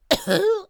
traf_damage3.wav